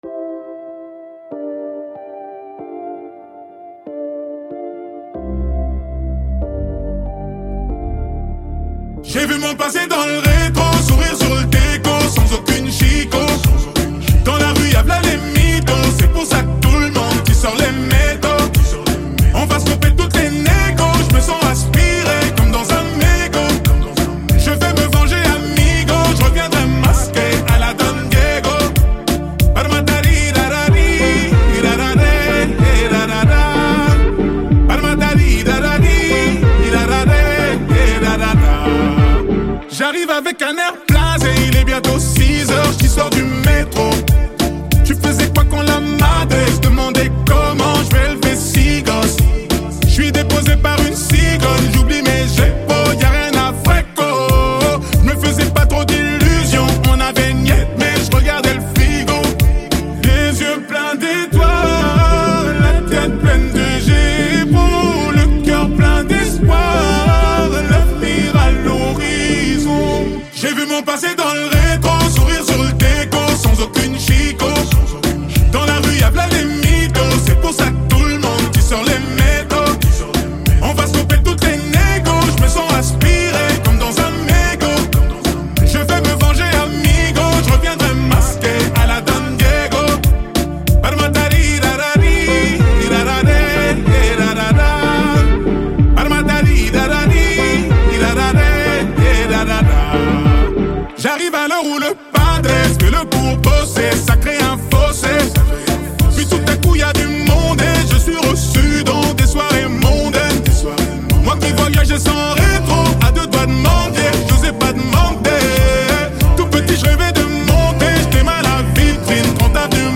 french rap Télécharger